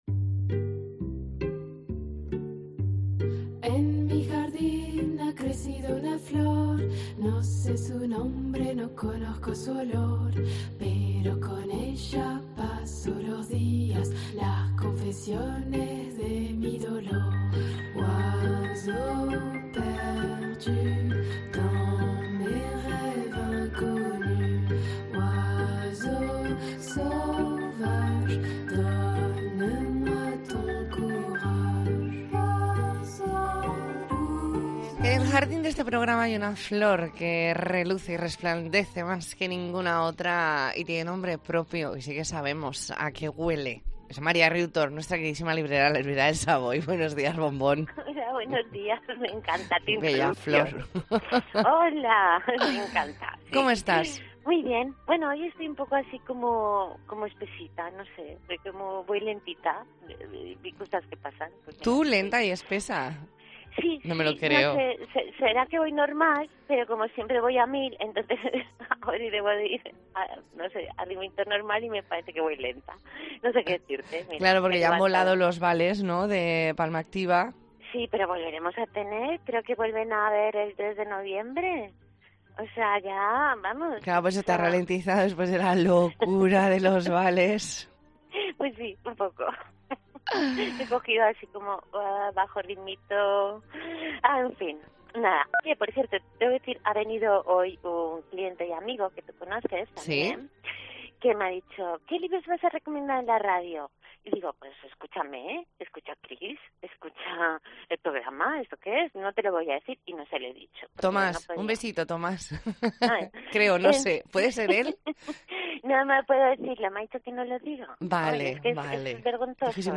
Entrevista en 'La Mañana en COPE Más Mallorca', martes 25 de octubre de 2022.